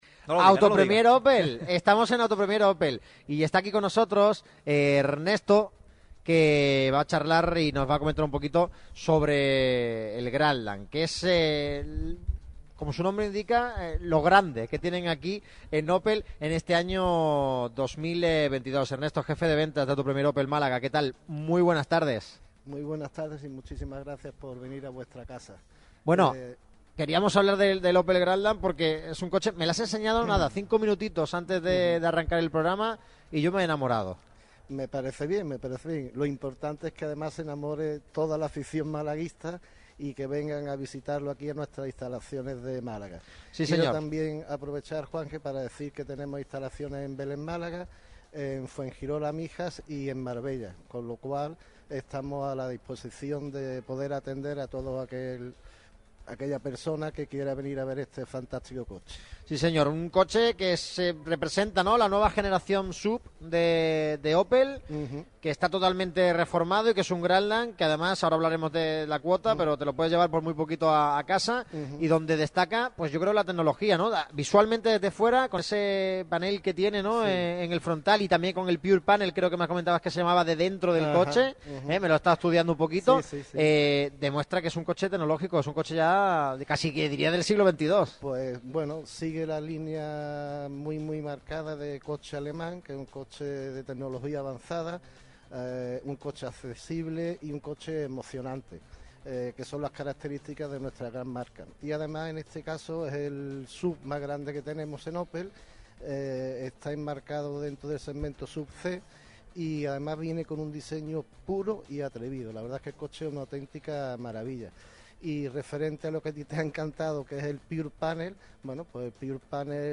Radio Marca Málaga ha cerrado su semana de programas en las instalaciones de Autopremier, concesionario oficial de Opel en la Costa del Sol, en la avenida Ortega y Gasset. Un programa con un intenso debate, centrado en el partidazo de este fin de semana que se vivirá en La Rosaleda entre el Málaga CF y el Oviedo.